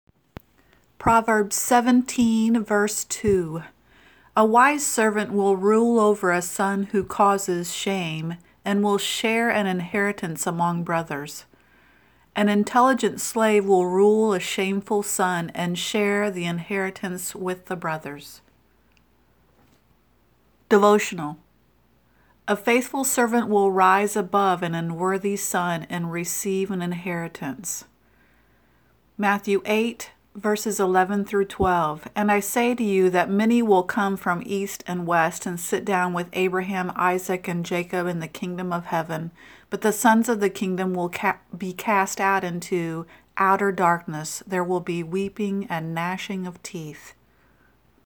Proverbs-17-2-Childrens-Bible-Verse.mp3